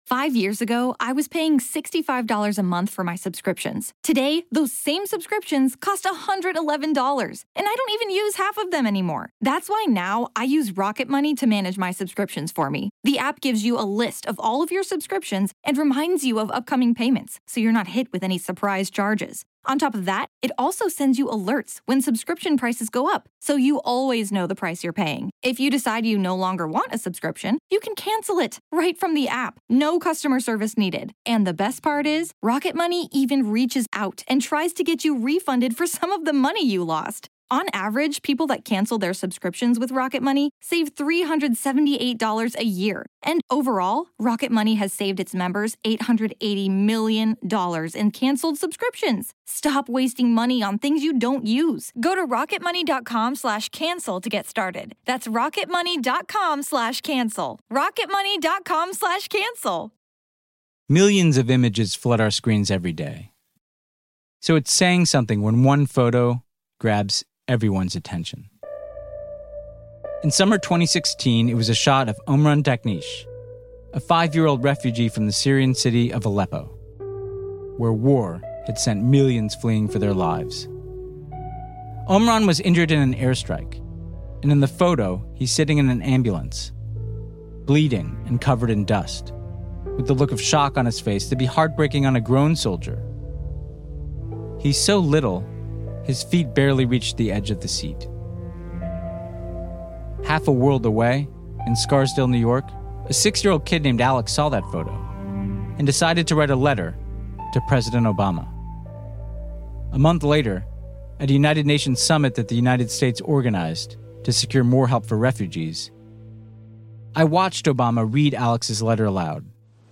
Host Ben Rhodes talks to